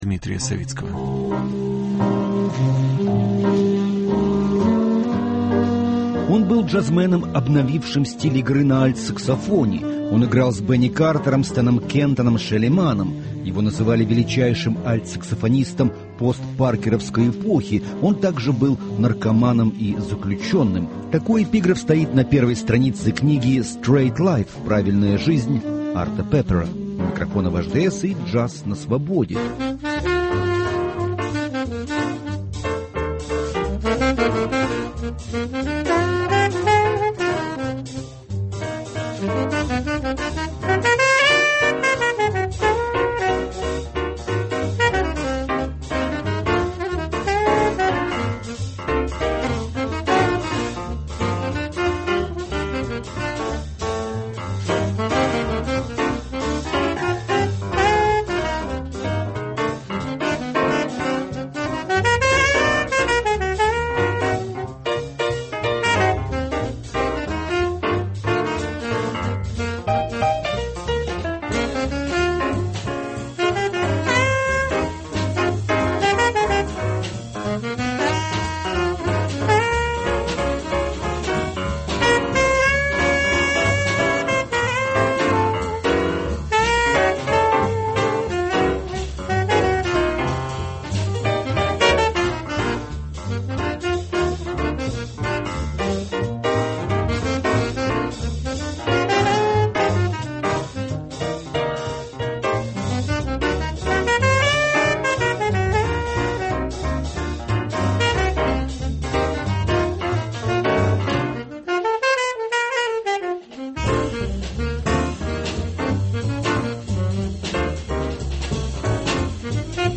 «Джаз на Свободе»: саксофонист Арт Пеппер